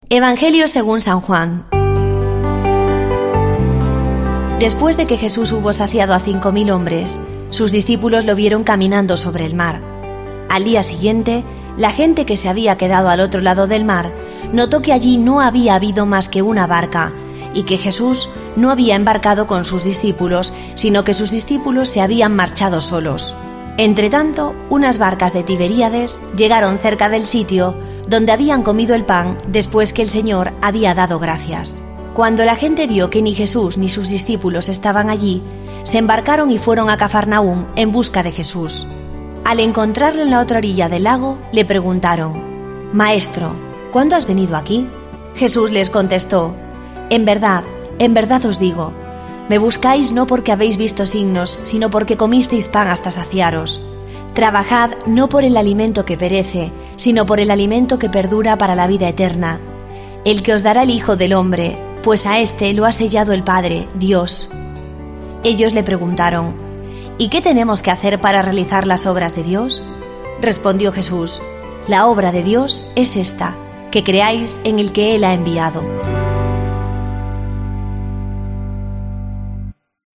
Lectura del santo Evangelio según san Juan 6,22-29